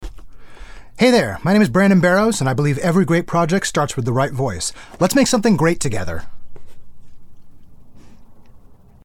My voice is warm, strong, explicit and clear and is suitable for audio-books, e-learning, commercial, documentaries, telephone, video-games, commercials, animation, bussiness and more....
0819Conversational_Introduction.mp3